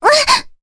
Cassandra-Vox_Damage_jp_02.wav